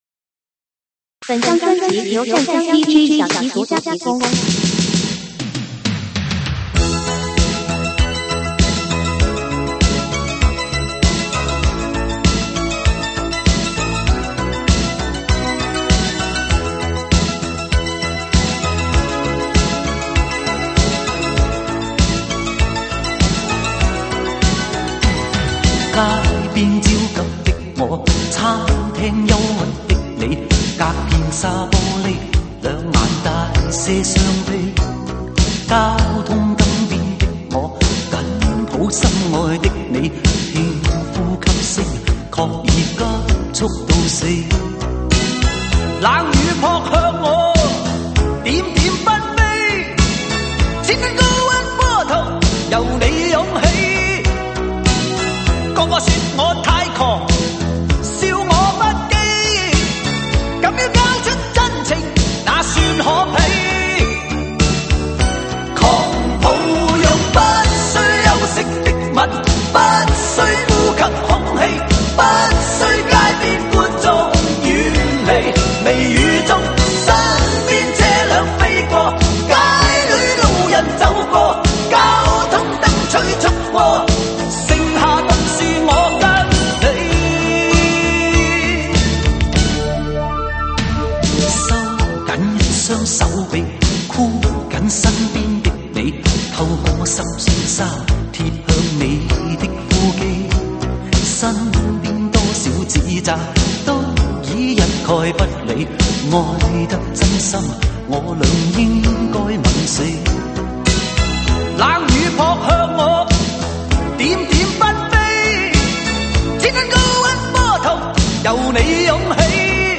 粤语经典